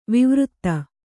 ♪ vivřtta